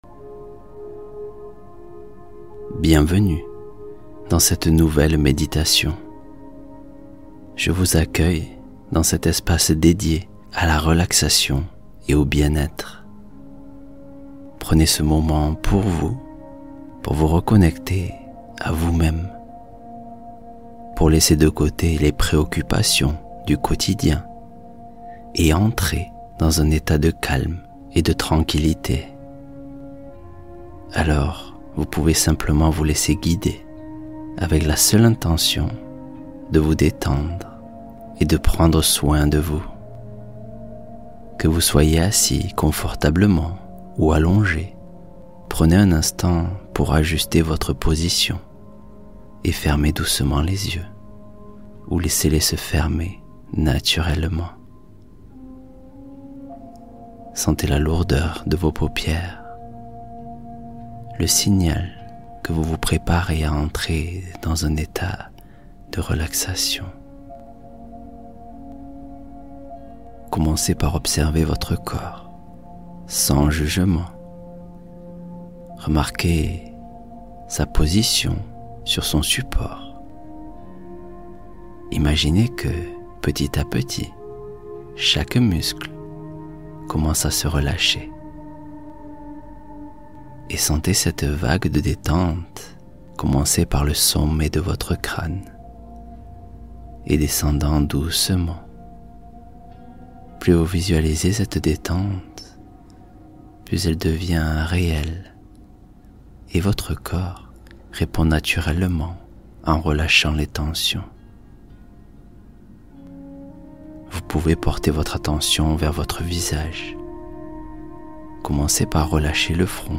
Confiance en toi : activation intérieure par une méditation puissante